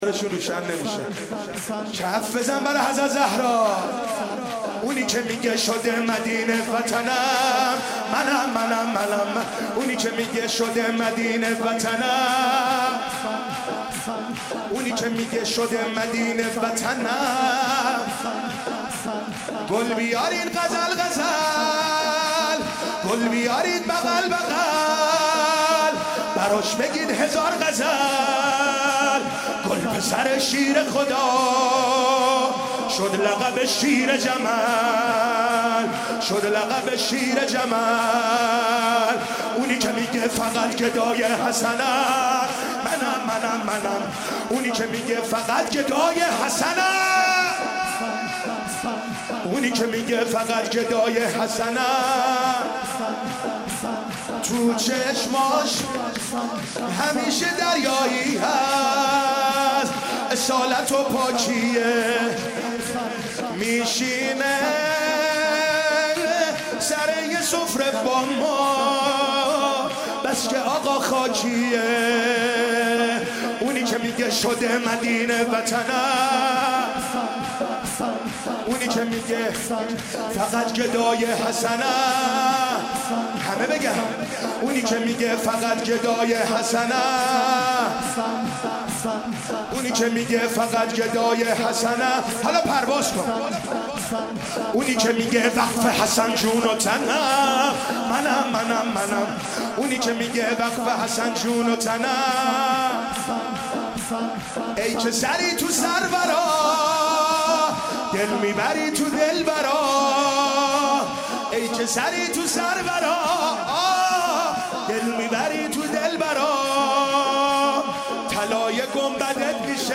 مراسم جشن میلاد امام حسن مجتبی با مداحی
مدیحه سرائی ، سرود
سرود ، روضه ، صحبت